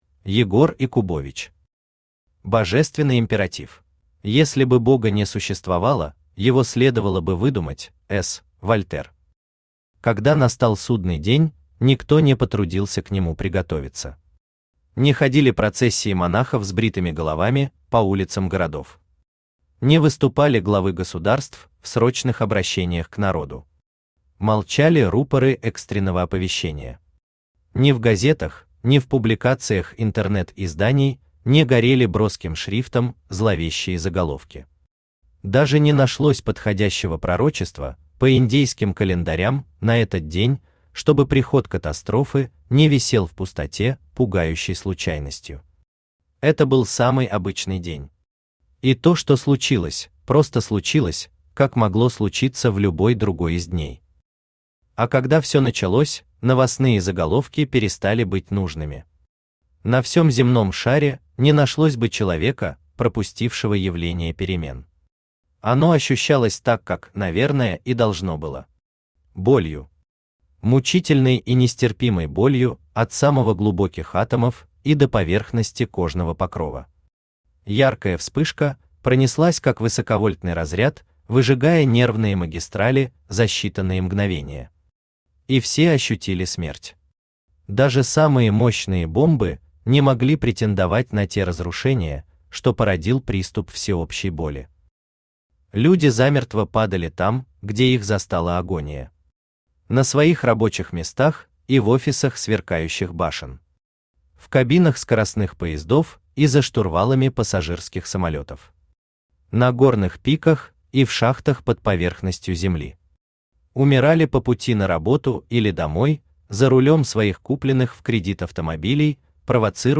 Aудиокнига Божественный императив Автор Егор Якубович Читает аудиокнигу Авточтец ЛитРес.